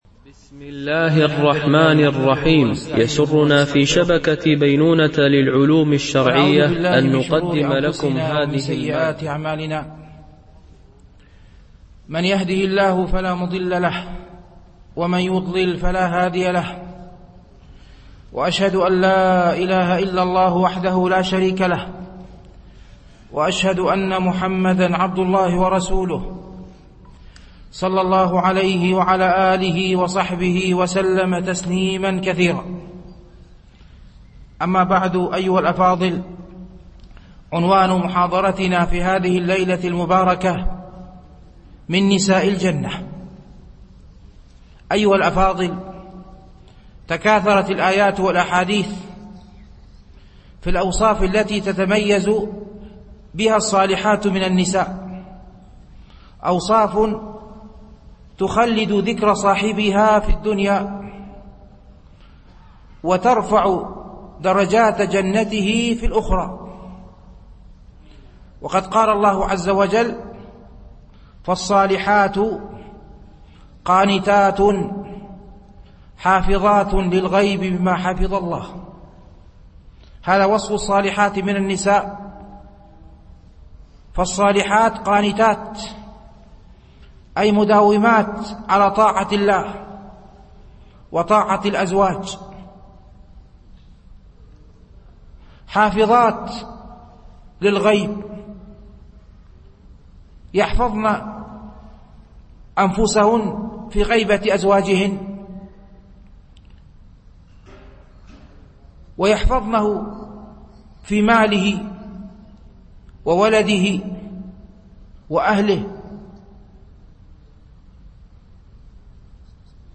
التنسيق: MP3 Mono 22kHz 32Kbps (CBR) التفريغ حمل تفريغ